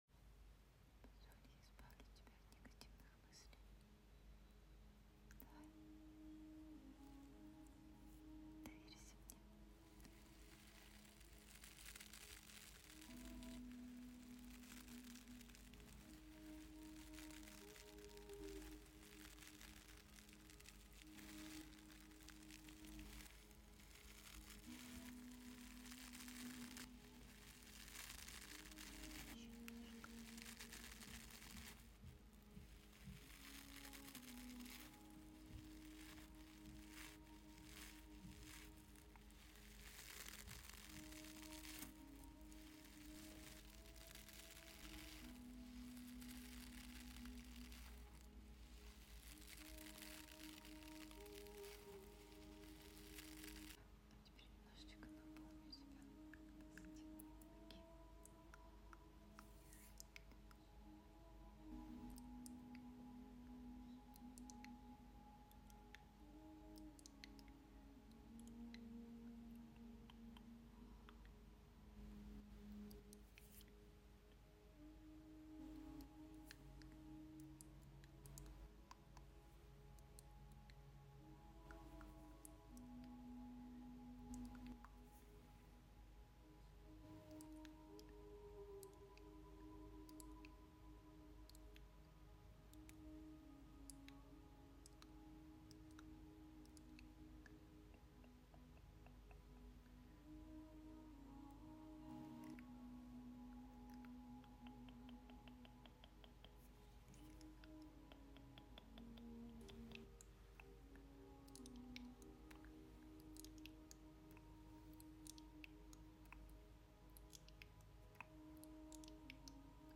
ASMR, relaxation before sleep, getting sound effects free download